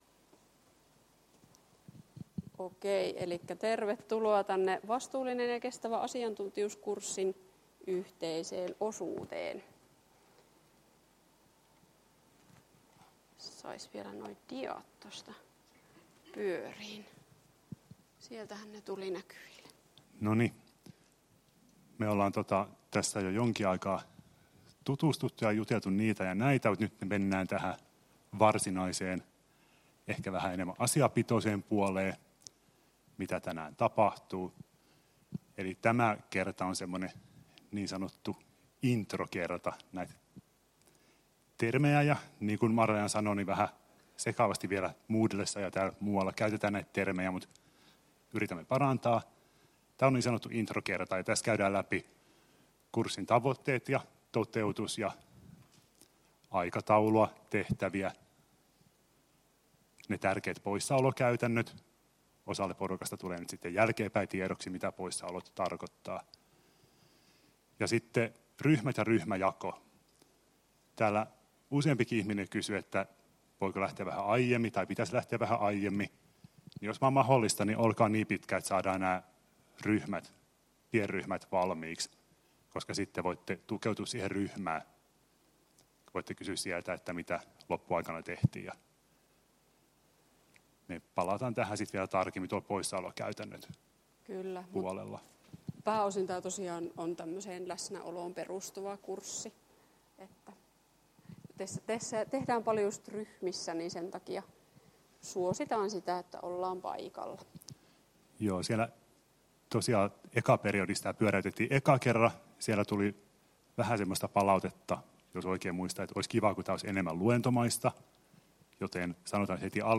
Aloitusluento, periodi 2 — Moniviestin